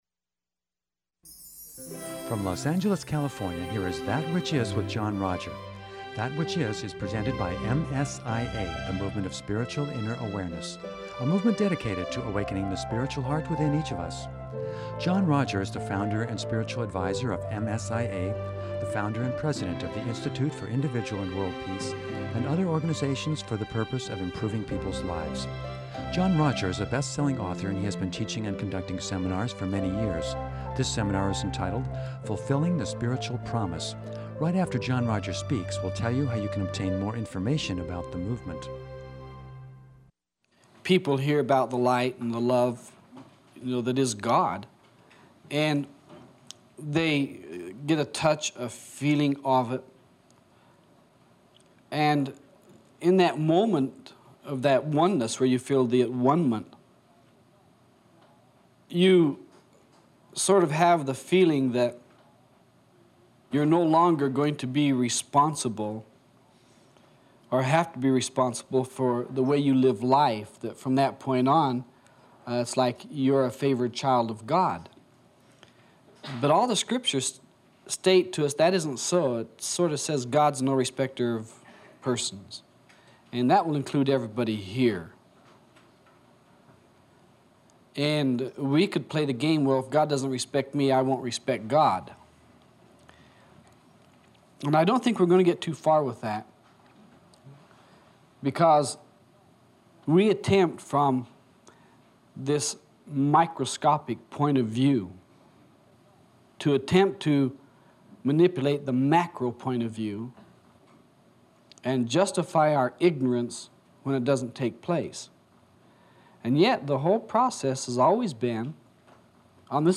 In this wonderfully telling seminar